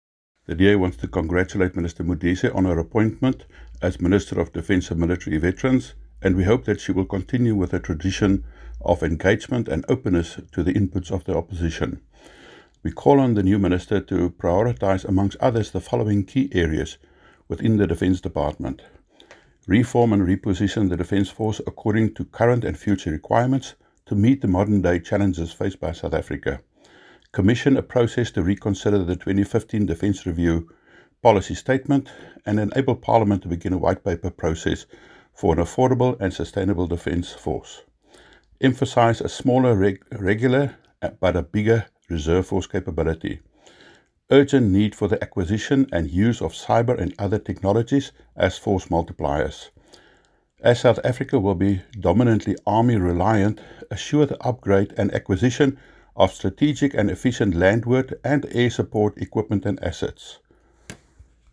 Afrikaans soundbites by Kobus Marais MP
Kobus-English-.mp3